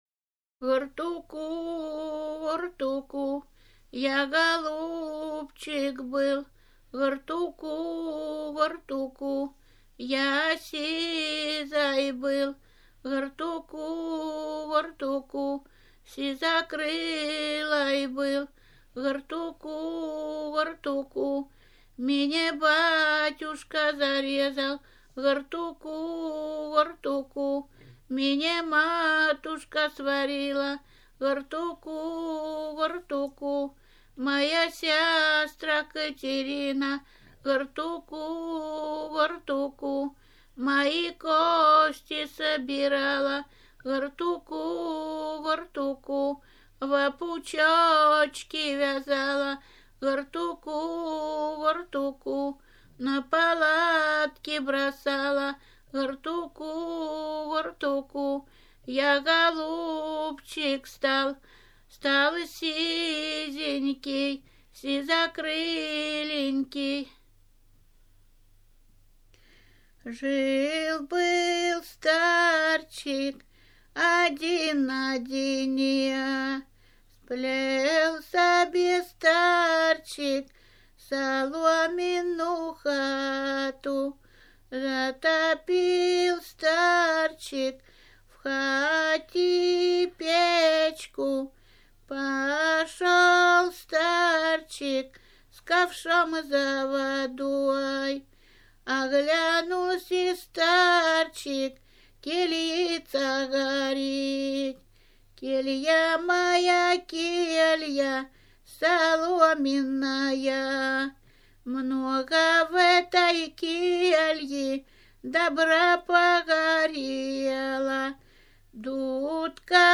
08_Гартуку,_вартуку_(колыбельные).mp3